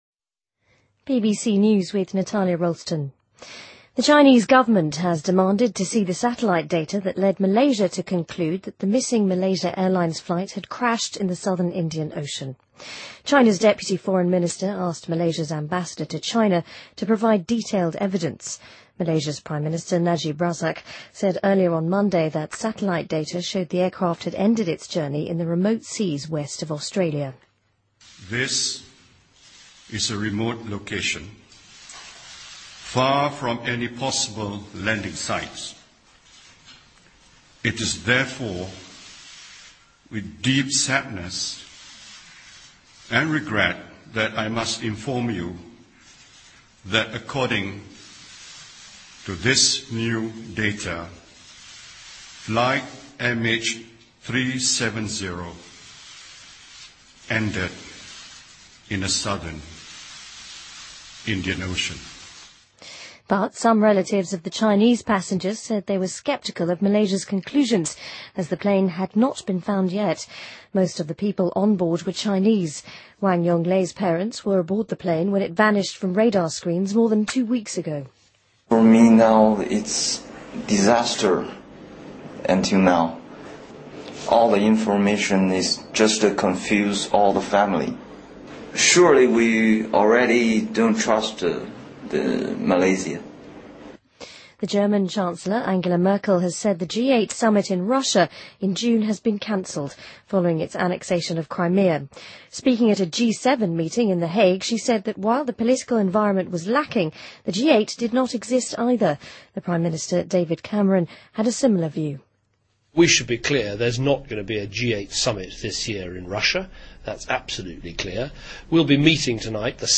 BBC news,2014-03-25